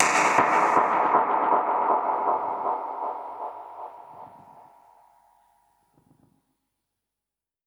Index of /musicradar/dub-percussion-samples/125bpm
DPFX_PercHit_A_125-08.wav